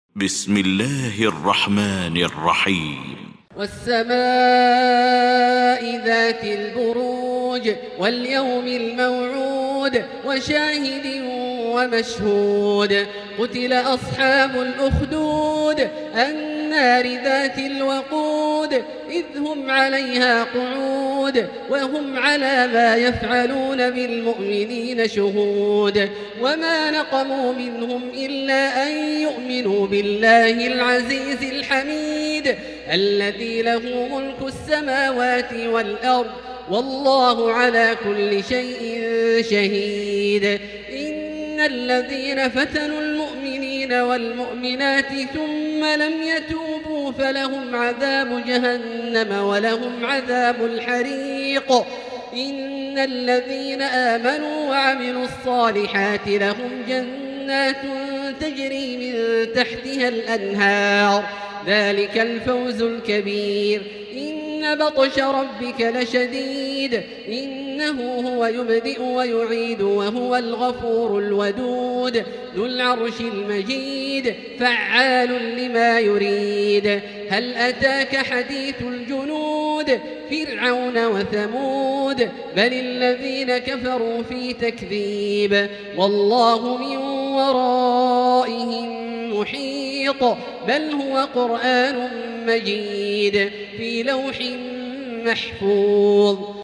المكان: المسجد الحرام الشيخ: فضيلة الشيخ عبدالله الجهني فضيلة الشيخ عبدالله الجهني البروج